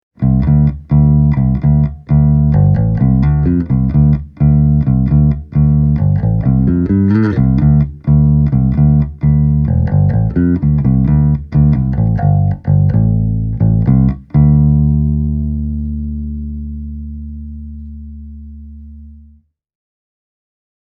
My old Jazz Bass sounds nice and chewy, with all of its ”wood” and tone intact:
Character off – EQ off